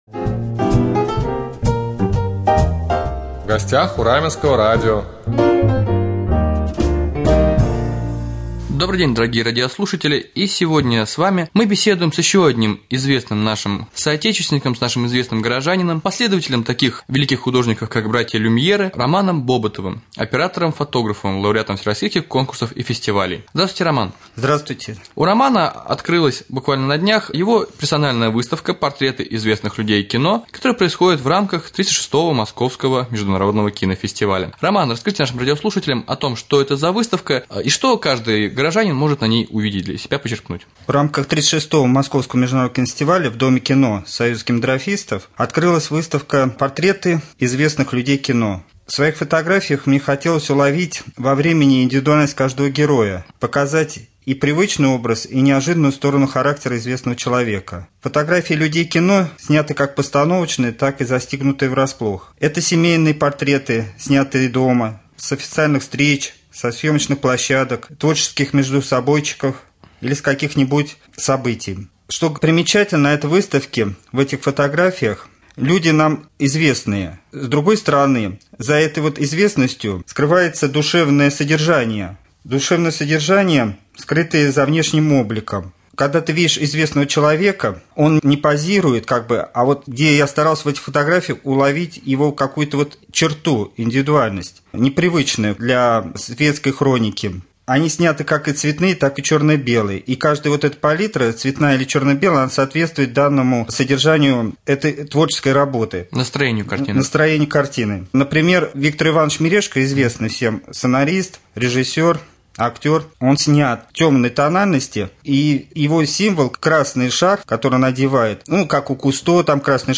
Гость студии Раменского радио оператор